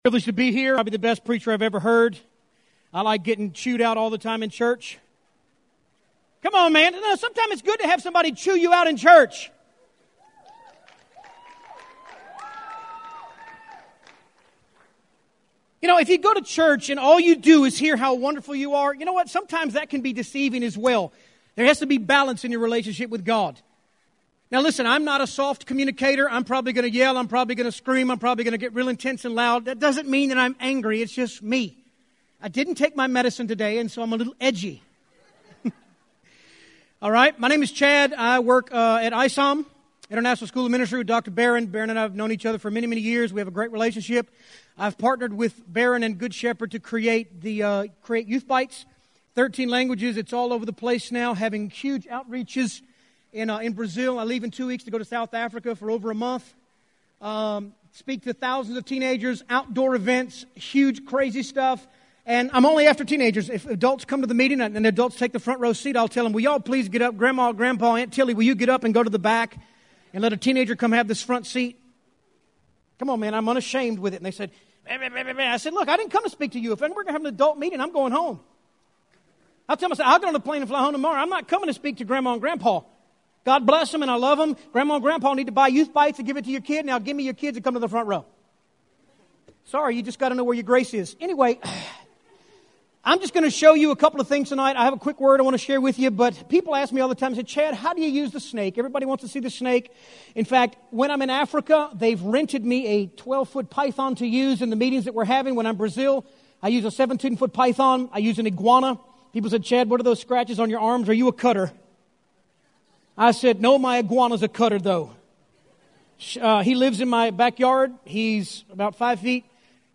Youth Service